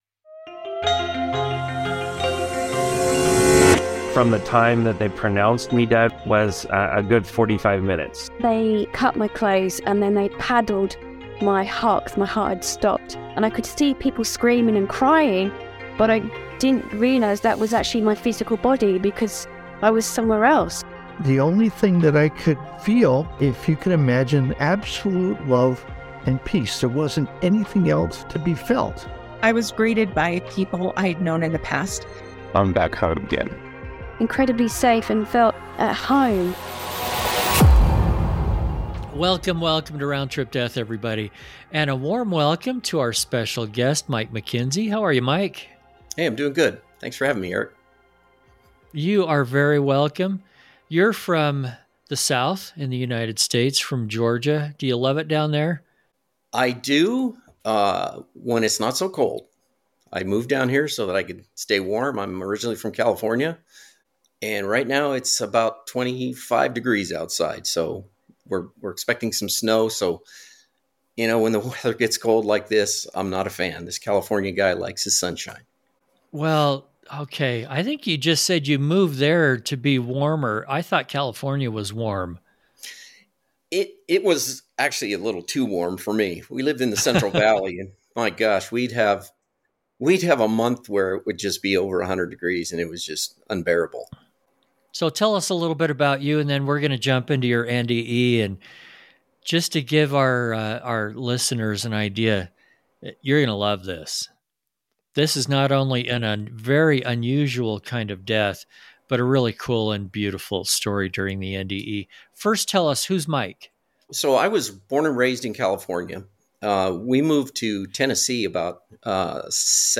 Round Trip Death podcast features discussions with people who have actually died, visited the other side, and returned to talk about it.